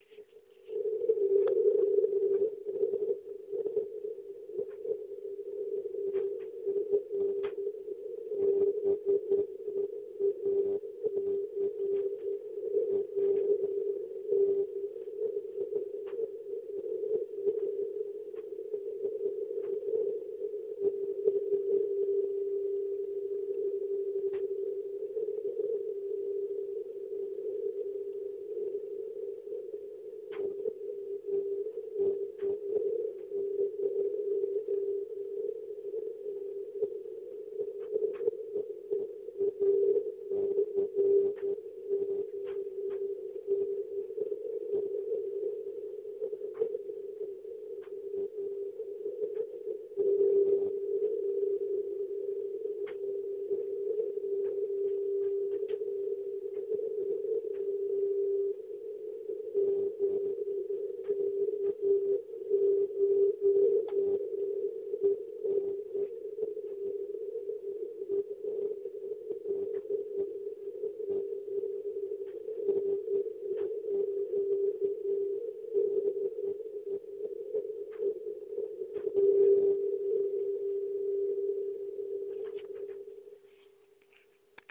描述：捷克无线电信标
Tag: 莫氏 CW